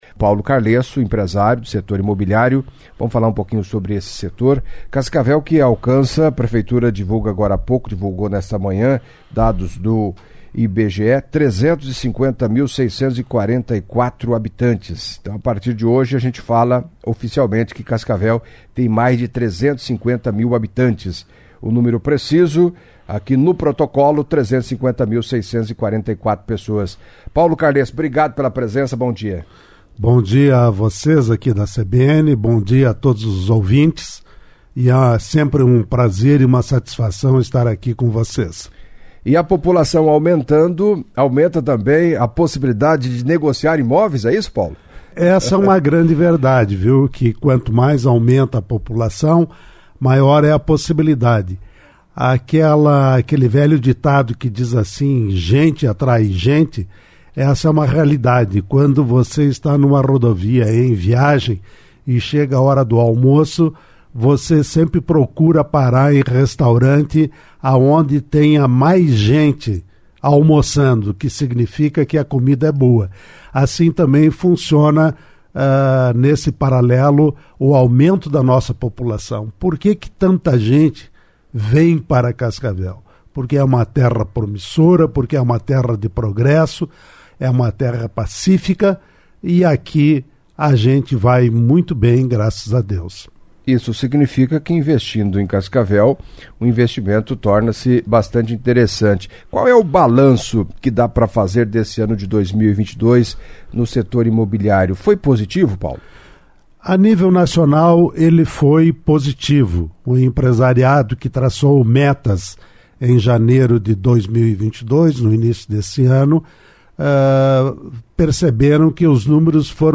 Em entrevista à CBN Cascavel nesta quinta-feira (29)